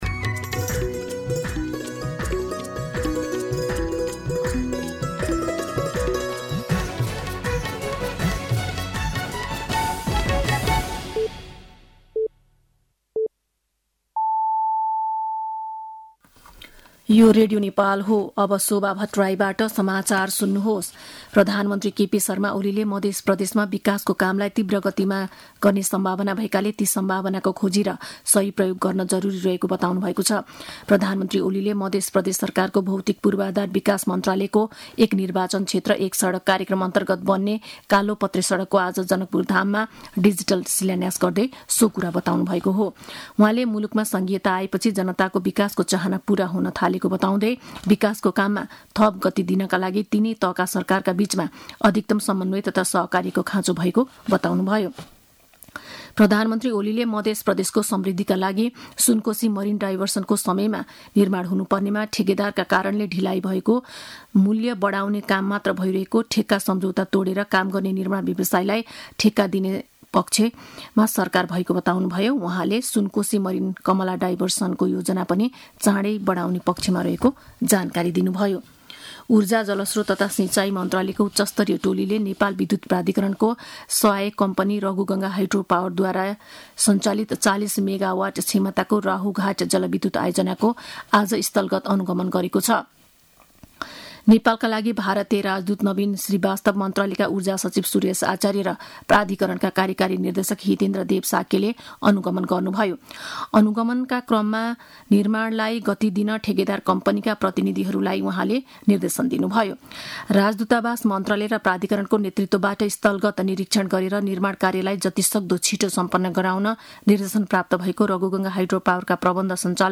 साँझ ५ बजेको नेपाली समाचार : १८ जेठ , २०८२
5.pm-nepali-news-.mp3